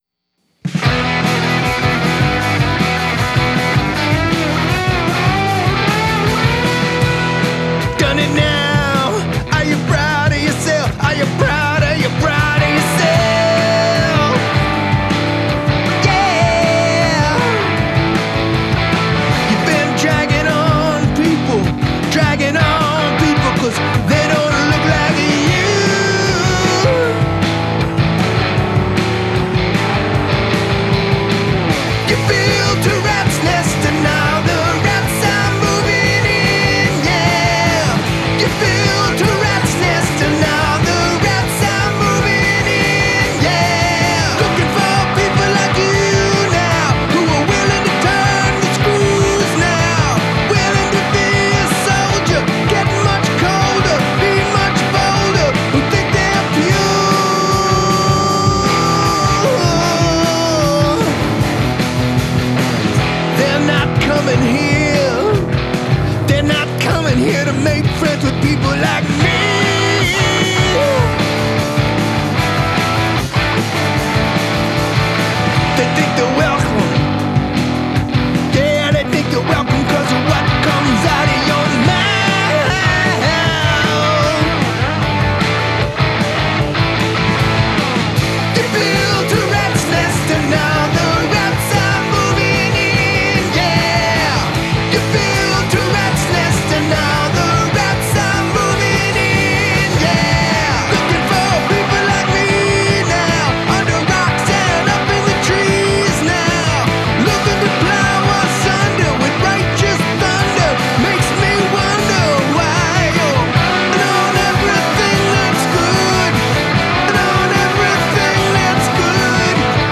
It’s punk rock. It’s loud.